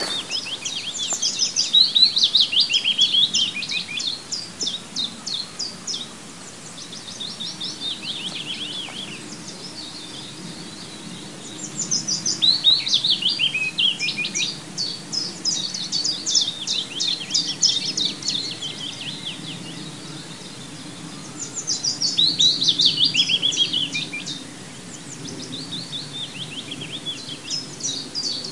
荷兰的鸟类 " 梅里桑附近沙坑附近的鸟类
描述：南荷兰弗拉基岛Melissant附近沙坑的鸟类
Tag: 场记录 春天 鸟的歌声